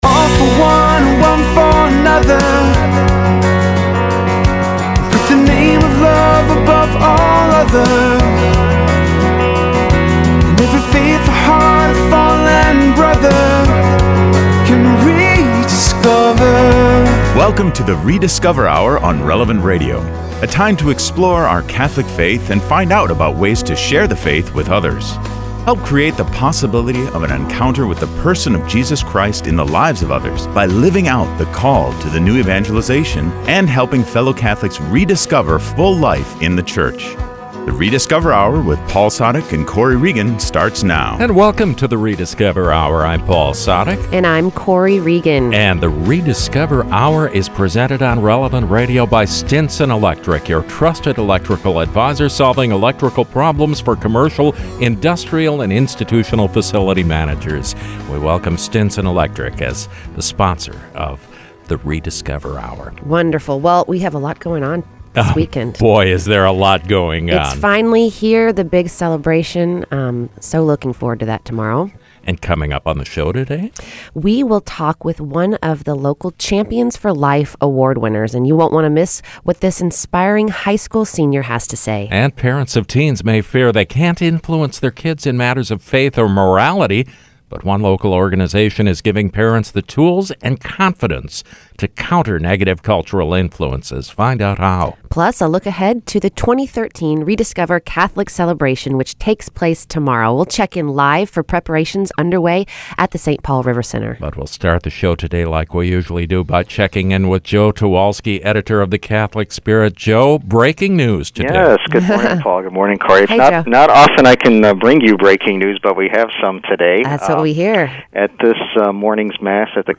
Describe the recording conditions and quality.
Plus, we’ll check in live from the Saint Paul RiverCentre where preparations will be underway for the big 2013 Rediscover: Catholic Celebration.